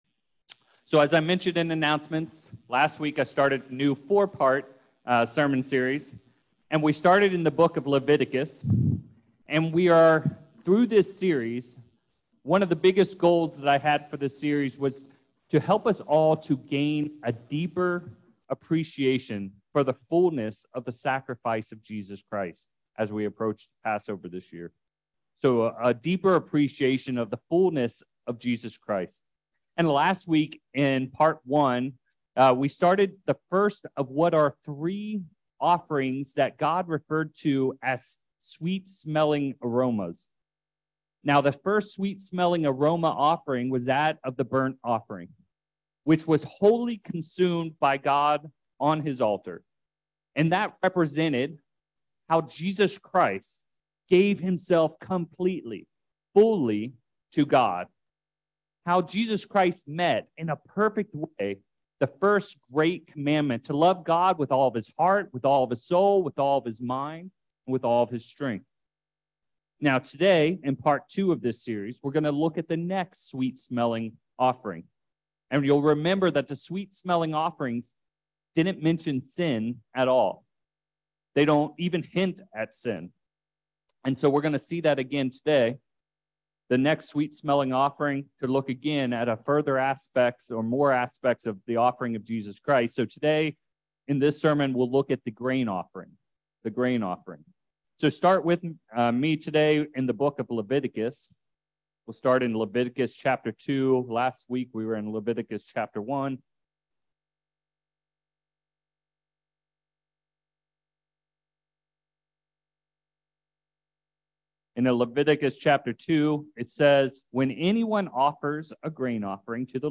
Orinda This is the second sermon in a series of four looking at the offerings of the beginning of Leviticus to help us grow in a deeper appreciation of Jesus Christ leading to Passover. In this sermon, we will look at the second offering in Leviticus and see how it represented Jesus Christ perfectly loving his neighbor. We’ll further see how all the different types of grain pointed to various aspects of our Savior’s life.